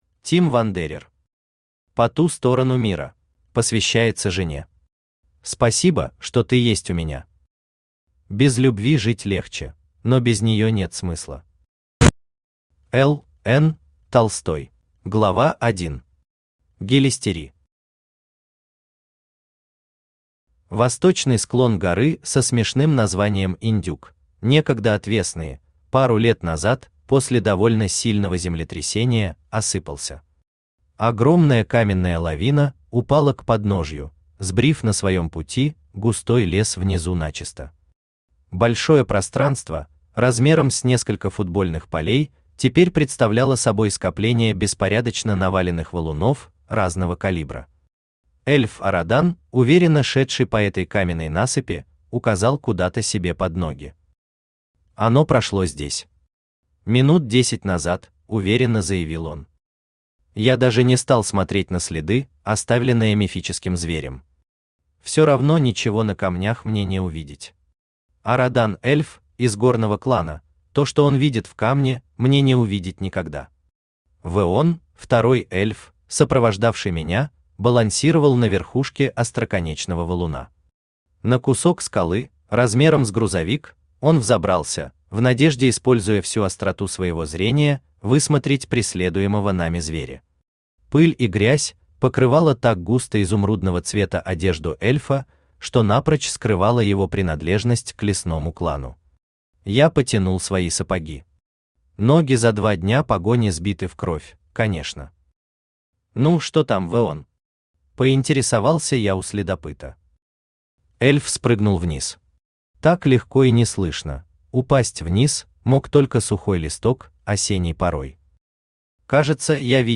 Аудиокнига По ту сторону мира | Библиотека аудиокниг
Aудиокнига По ту сторону мира Автор Тим Вандерер Читает аудиокнигу Авточтец ЛитРес.